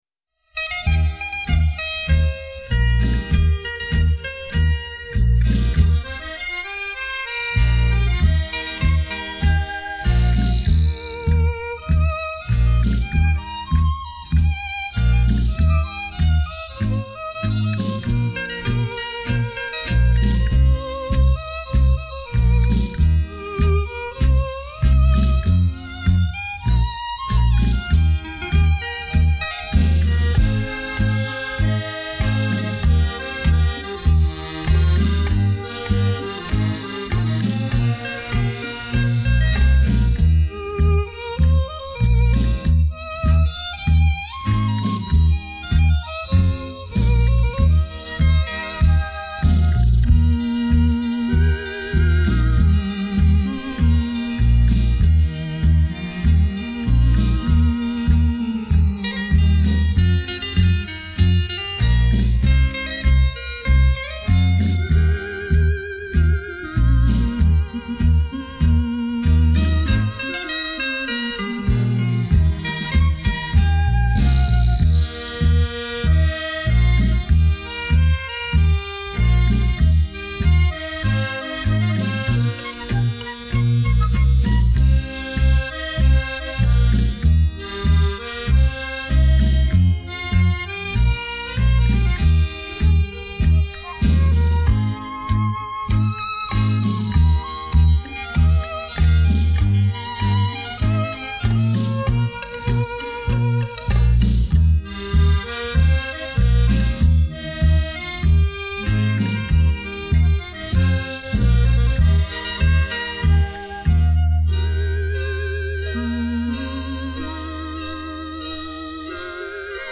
黑胶LP